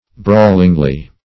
Meaning of brawlingly. brawlingly synonyms, pronunciation, spelling and more from Free Dictionary.
brawlingly - definition of brawlingly - synonyms, pronunciation, spelling from Free Dictionary Search Result for " brawlingly" : The Collaborative International Dictionary of English v.0.48: Brawlingly \Brawl"ing*ly\, adv.